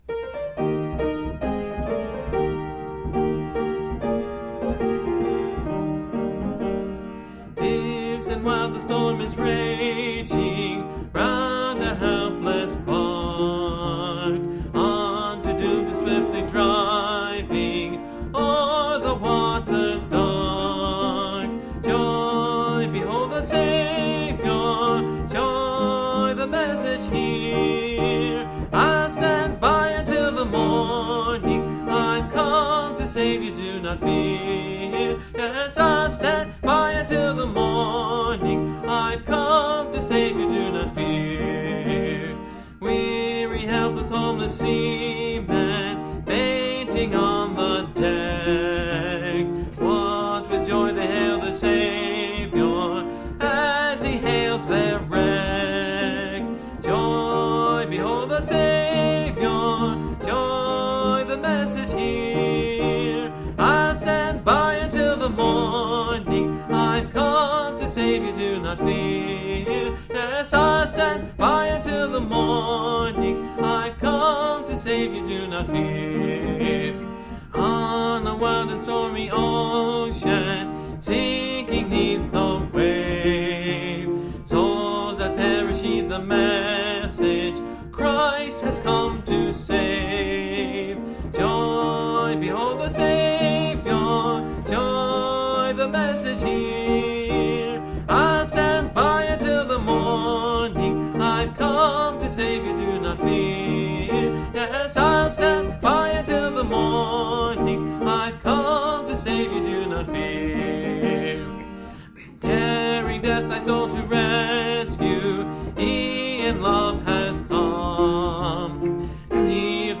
(Part of a series singing through the hymnbook I grew up with: Great Hymns of the Faith)
This particular hymn I don’t remember singing.